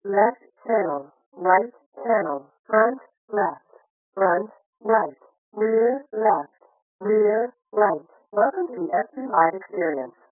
Lowering of frequencies above 1500 Hz with compression ratio=2
After compression and decompression
by the VLC codec at 8000 bps